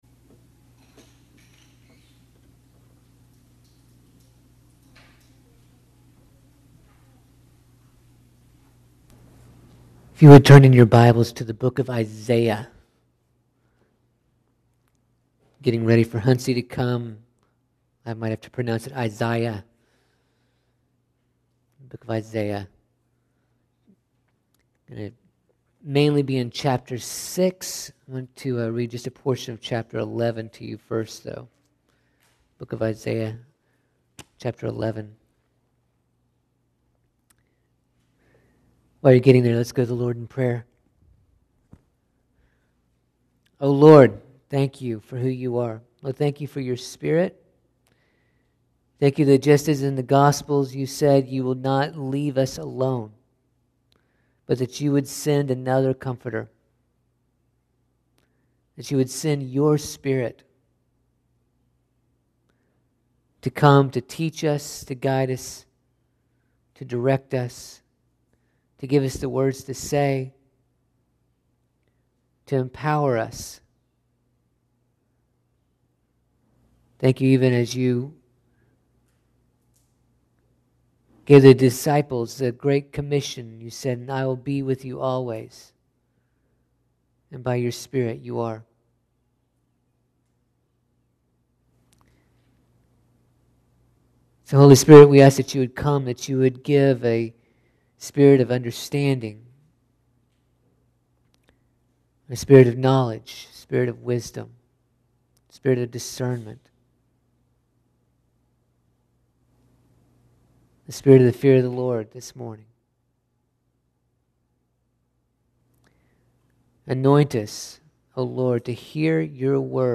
This message was preached at FCC in Franklin, CT.